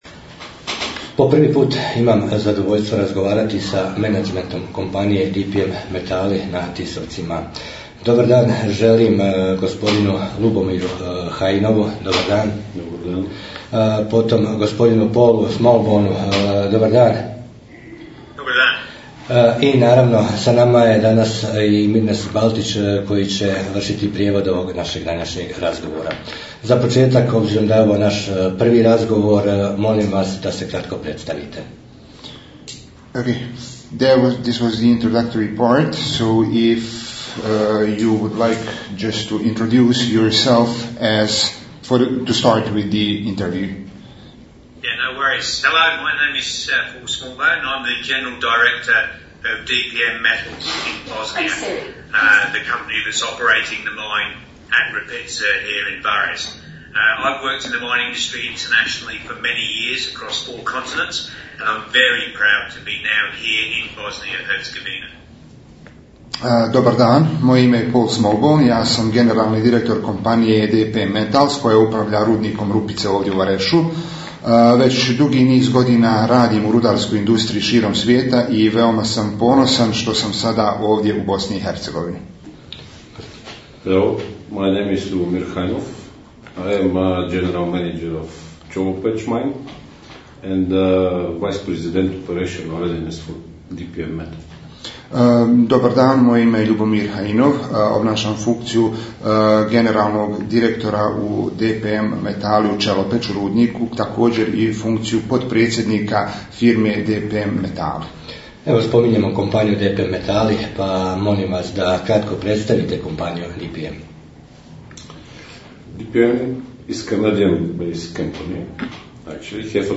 Razgovor s menadžmentom kompanije DPM metali